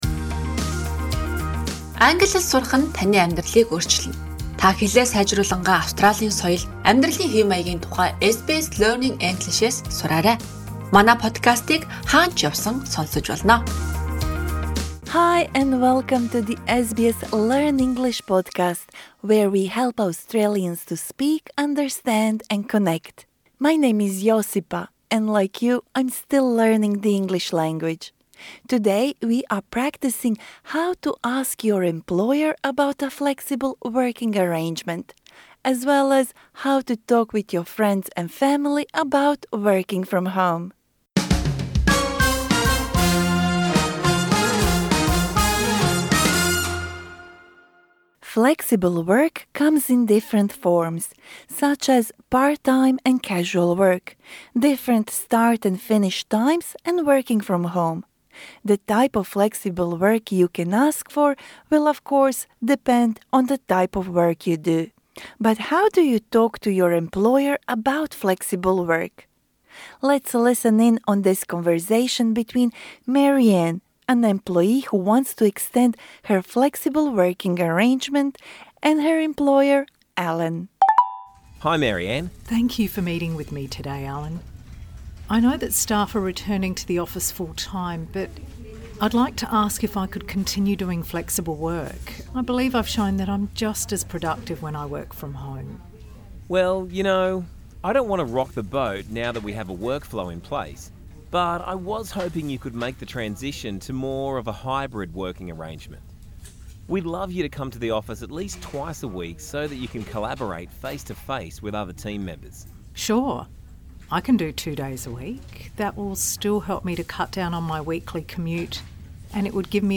This lesson suits upper-intermediate to advanced learners.